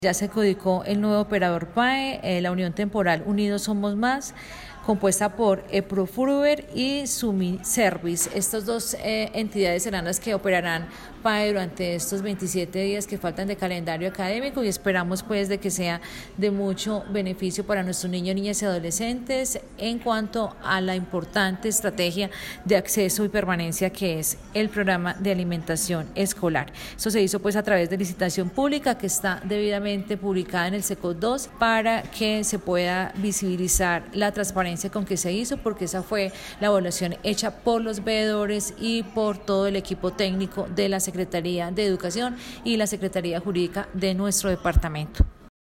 Audio: de Liliana María Sánchez, secretaria de Educación, sobre la adjudicación del PAE para el resto del calendario